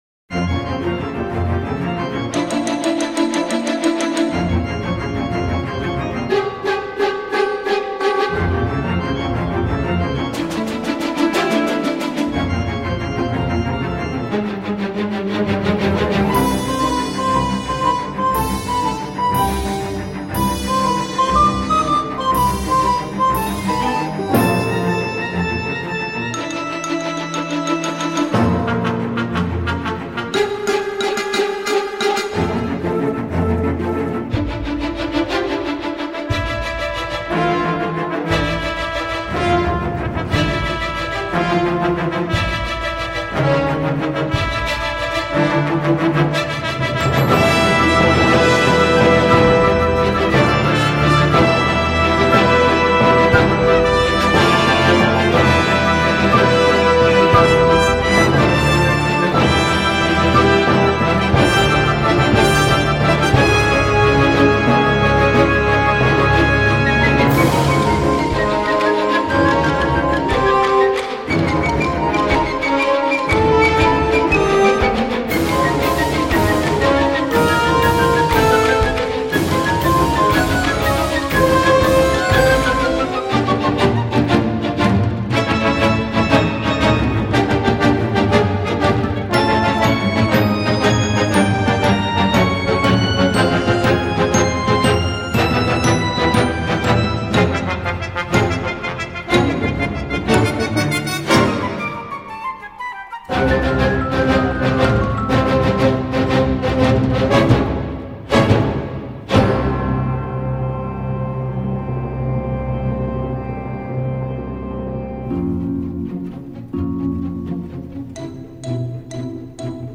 tour à tour sentimental, intimiste, majestueux ou médiéval